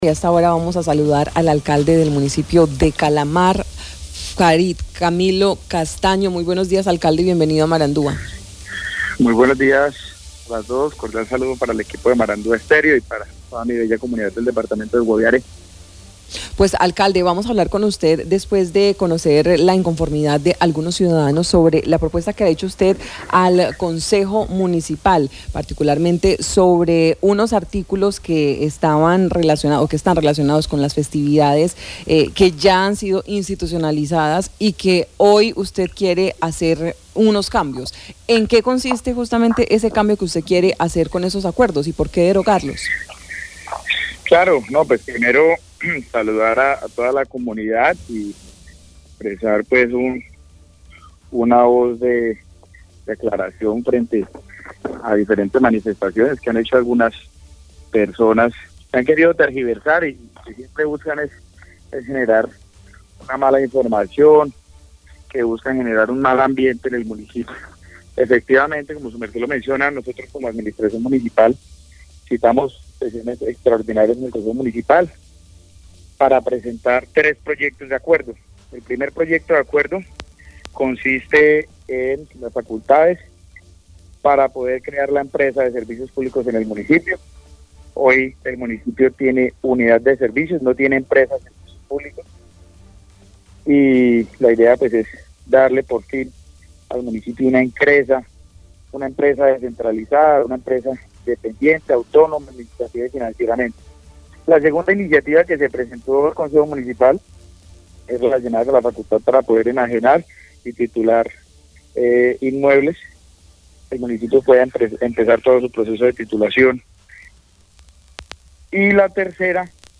Alcalde explica idea de crear el Festival Puerta del Chiribiquete en Calamar